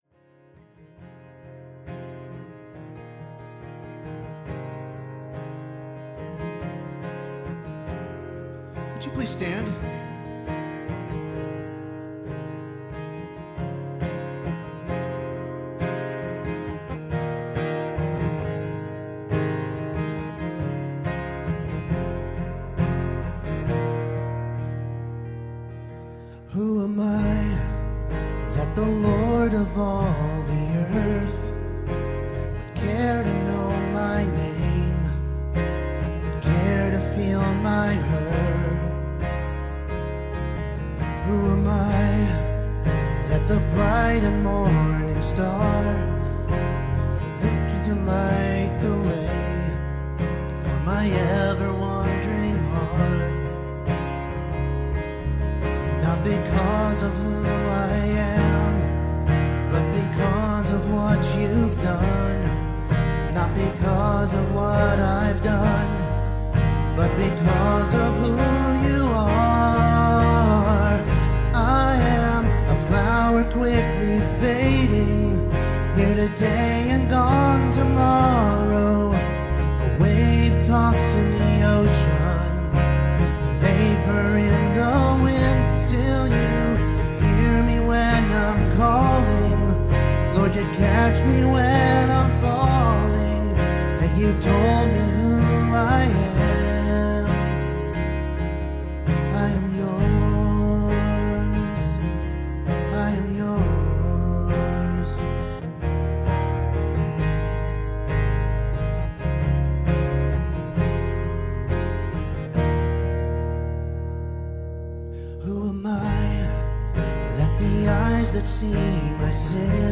Passage: Ephesians 1:5-10 Service Type: Sunday Morning